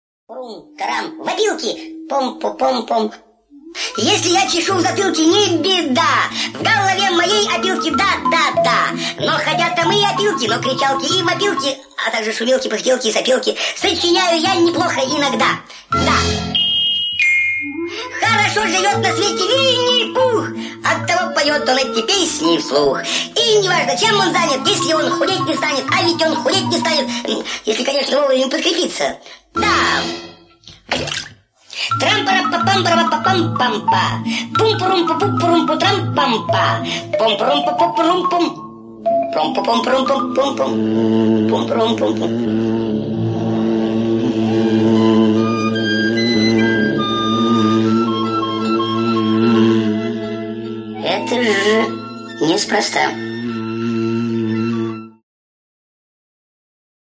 Детские песенки